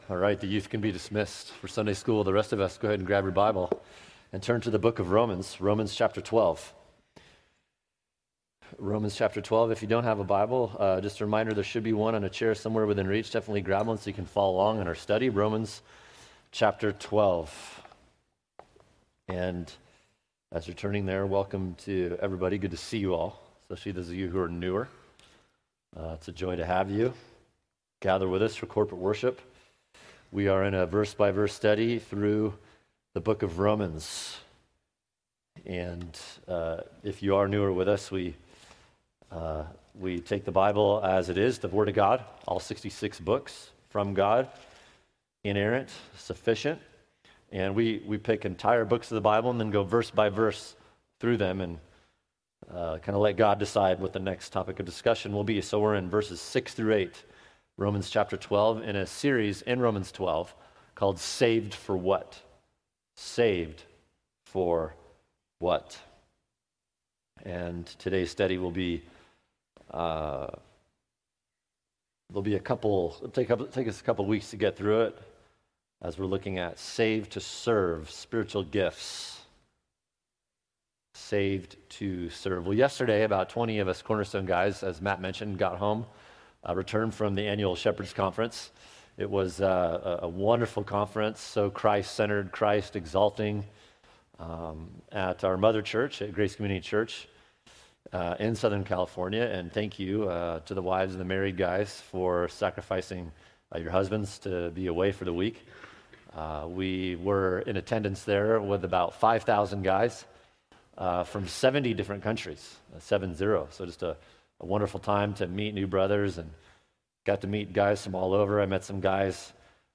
[sermon] Saved to Serve (Part 2): Spiritual Gifts Romans 12:4-8 | Cornerstone Church - Jackson Hole